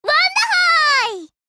WONDERHOY-SOUND-EFFECT-no-background-music.mp3